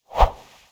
Close Combat Swing Sound 33.wav